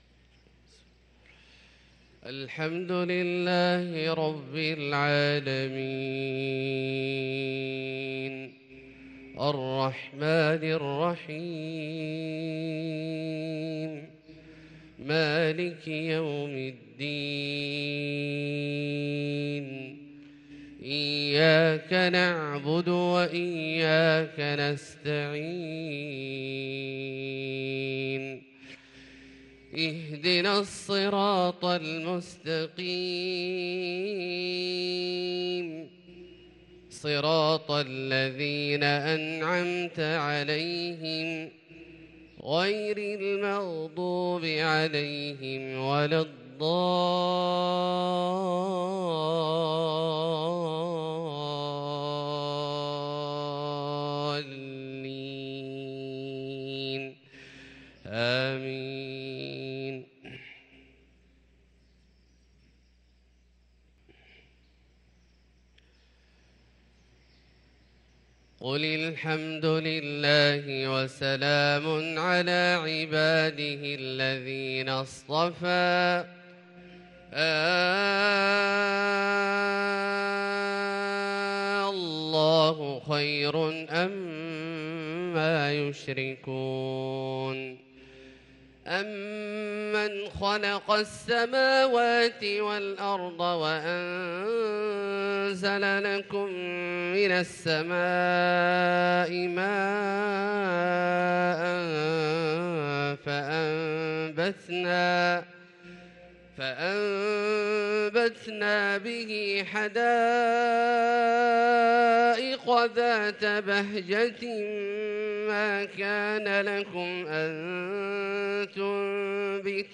صلاة العشاء للقارئ أحمد بن طالب حميد 14 ربيع الآخر 1444 هـ
تِلَاوَات الْحَرَمَيْن .